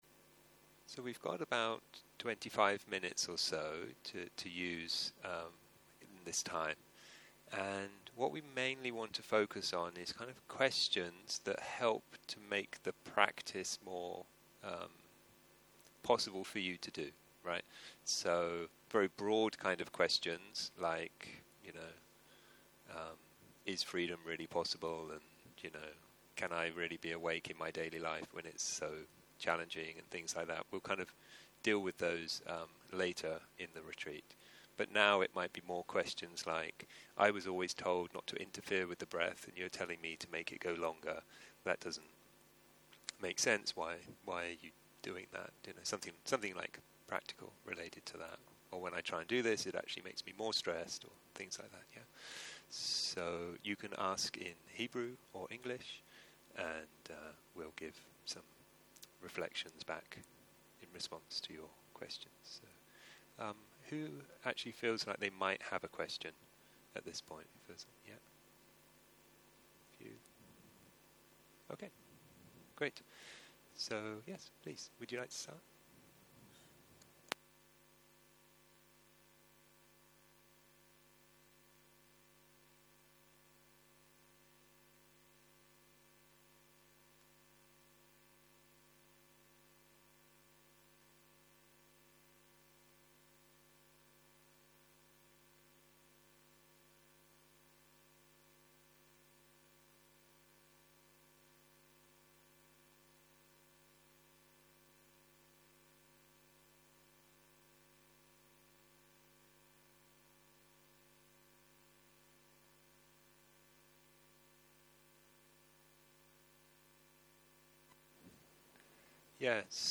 סוג ההקלטה: שאלות ותשובות